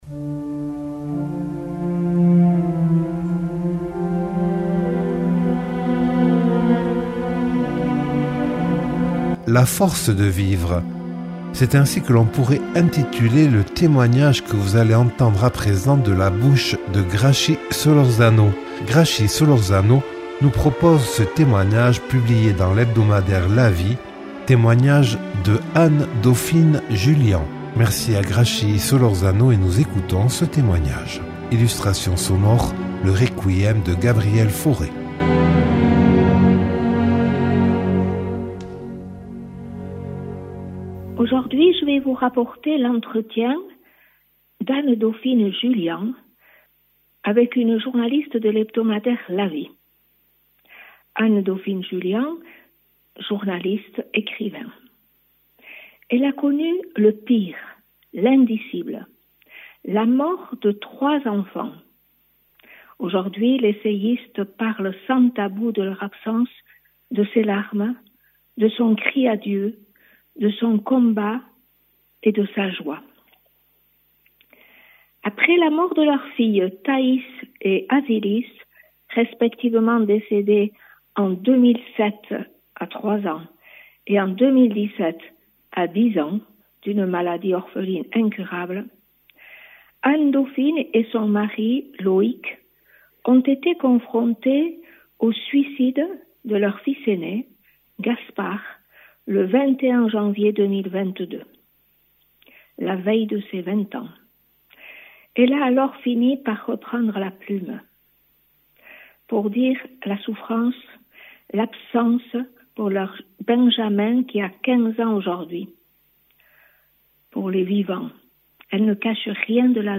Illustration musicale : Requiem de Gabriel Fauré.